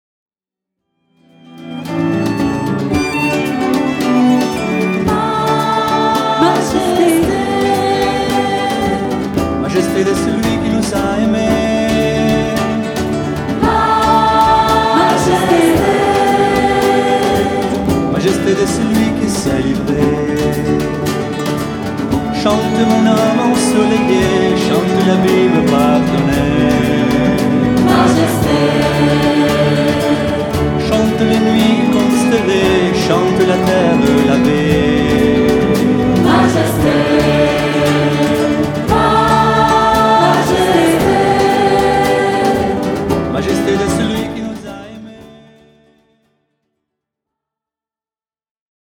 Enregistrement "live"